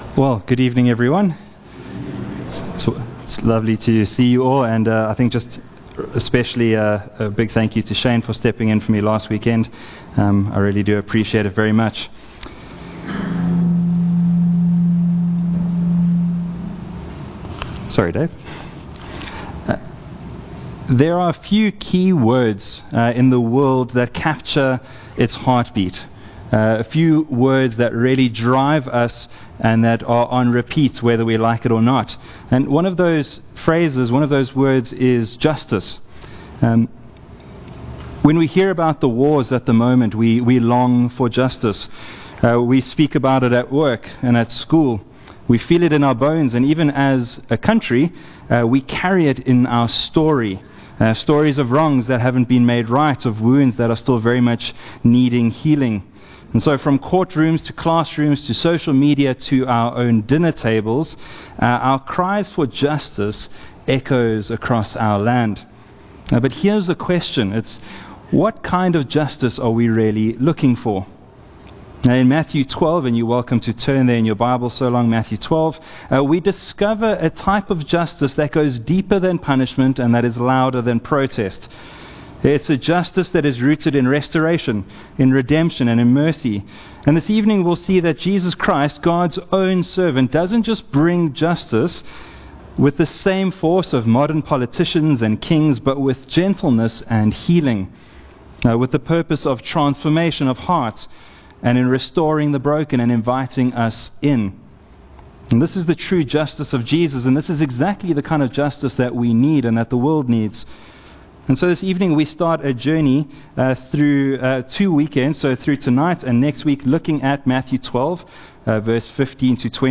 Sermons | Honeyridge Baptist Church